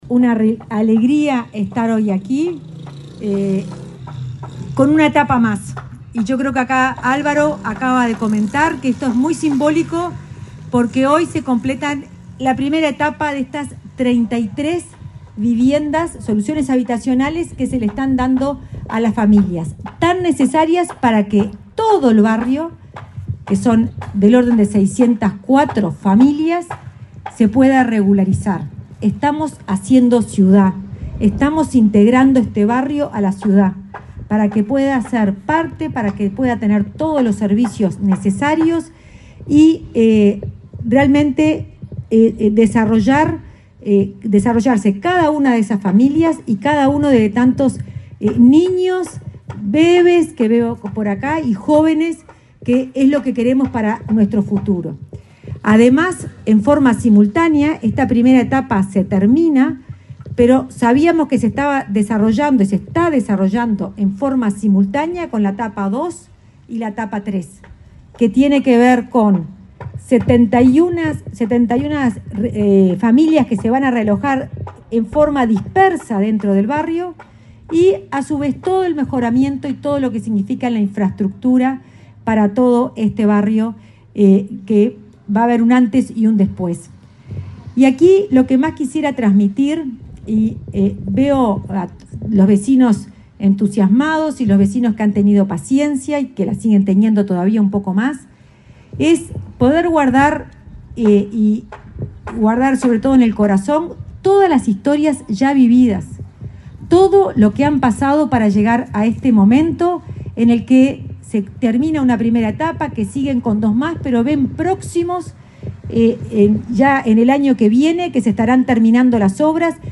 Palabras de la directora de Integración Social y Urbana del Ministerio de Vivienda, Florencia Arbeleche
Palabras de la directora de Integración Social y Urbana del Ministerio de Vivienda, Florencia Arbeleche 26/09/2024 Compartir Facebook X Copiar enlace WhatsApp LinkedIn La directora de Integración Social y Urbana del Ministerio de Vivienda, Florencia Arbeleche, participó, este jueves 26, en un acto de entrega de viviendas en el barrio Cotravi de Montevideo.